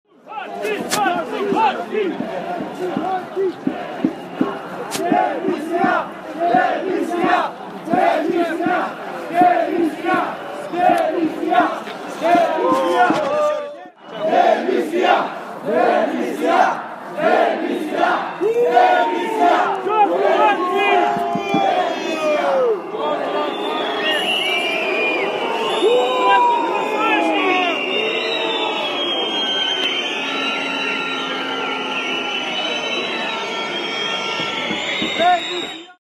Demonstranții scandeză în fața ministerului justiției de la București